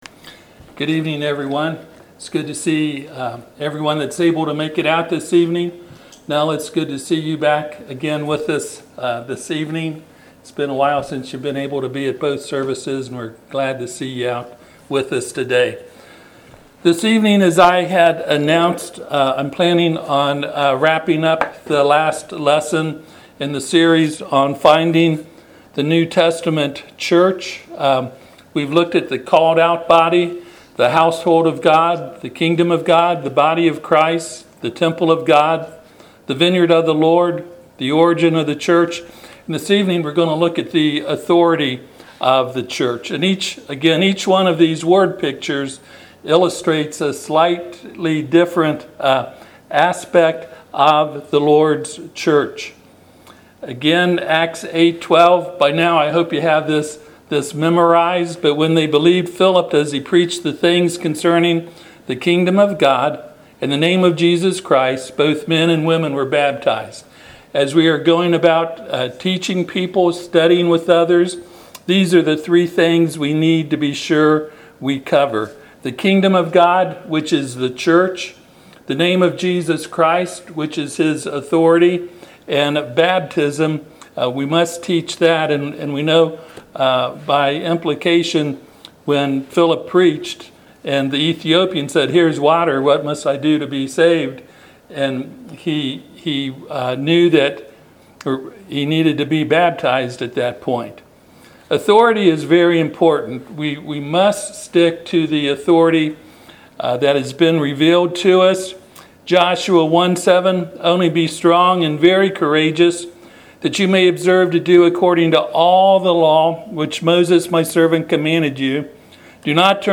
Service Type: Sunday PM Topics: Authority , Church , Pattern